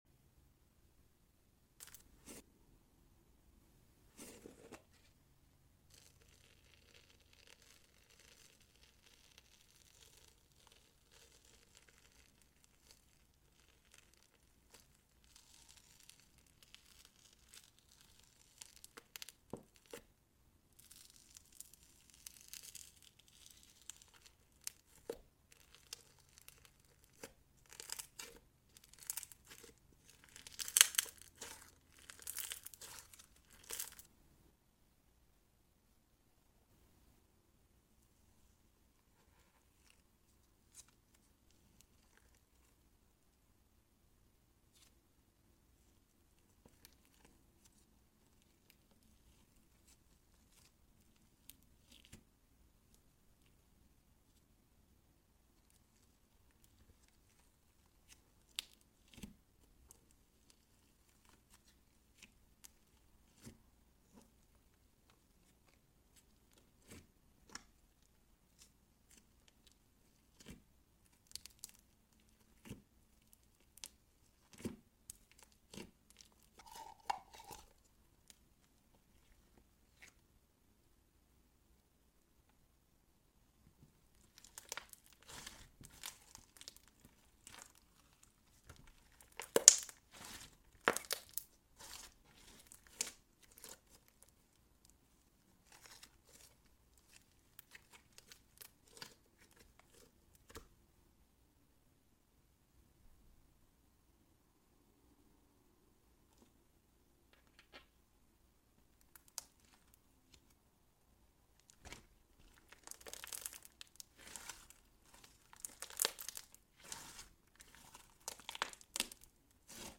Relaxing slime ASMR | Jack sound effects free download
DIY slime with clay topper mixing | Satisfying slime sounds